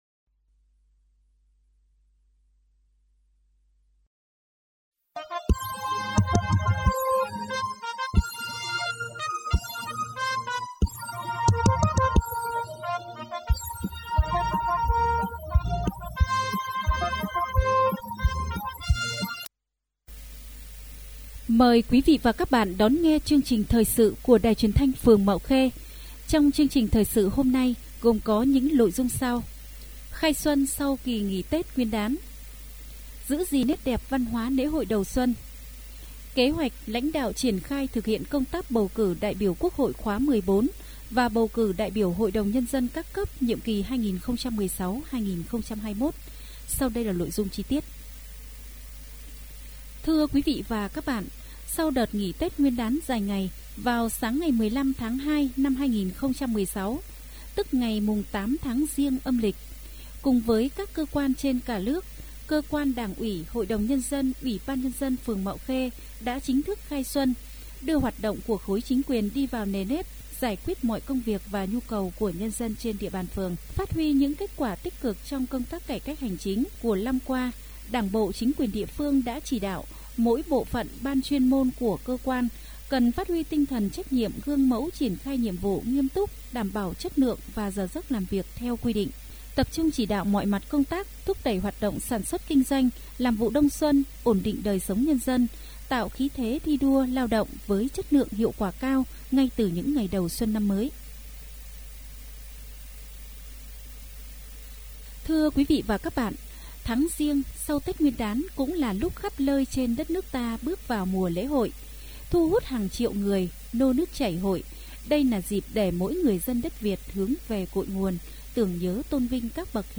Chương trình được phát trên sóng FM truyền thanh phường Mạo Khê ngày 15 tháng 2 năm 2016